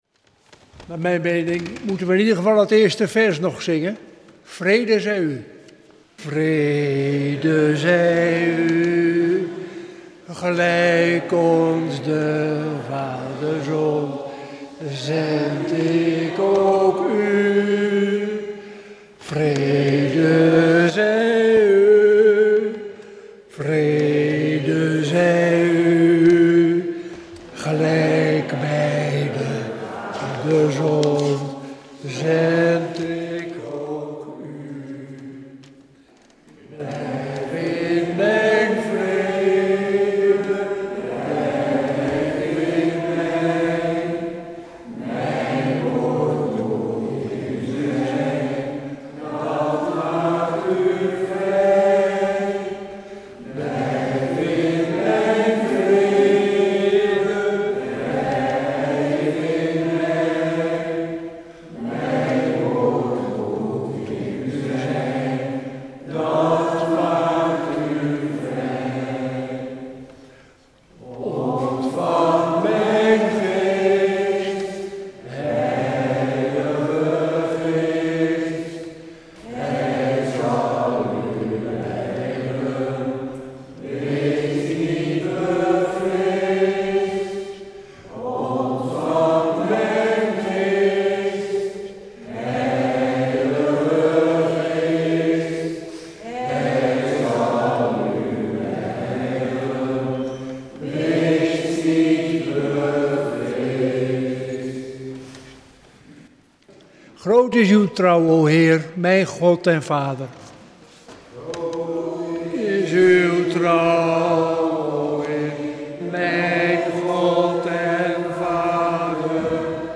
 Luister deze kerkdienst hier terug: Alle-Dag-Kerk 2 juli 2024 Alle-Dag-Kerk https